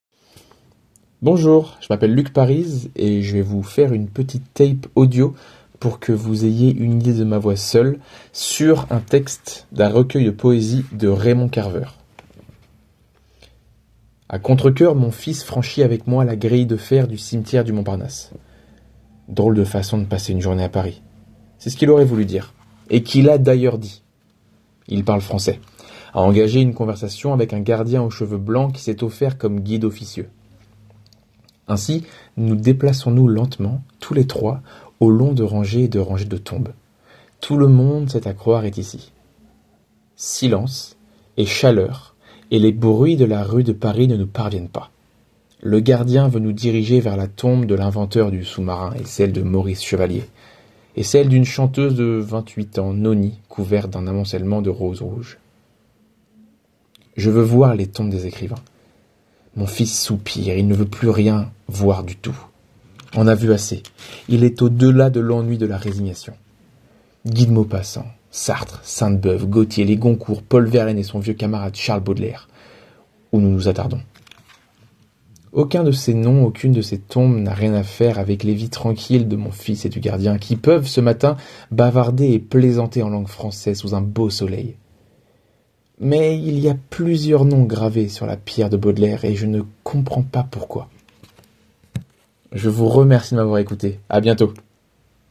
Voix Lecture